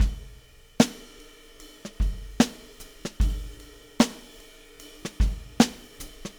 Weathered Beat 04.wav